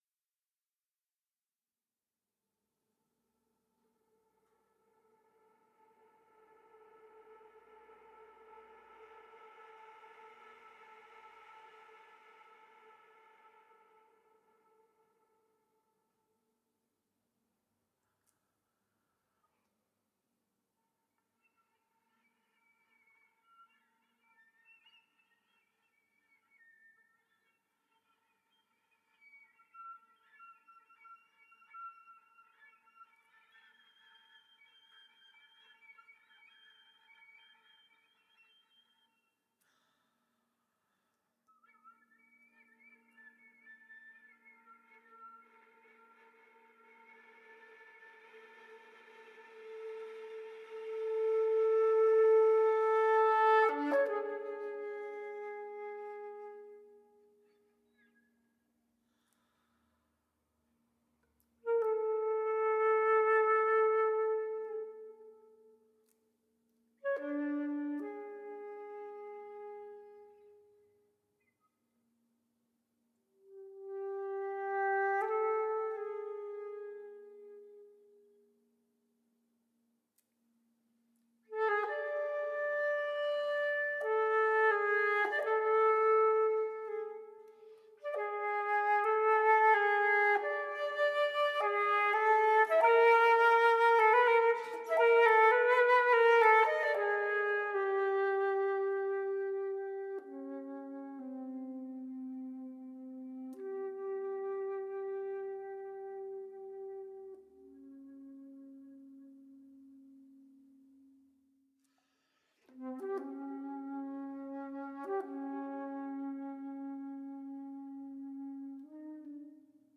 Flûte Traversière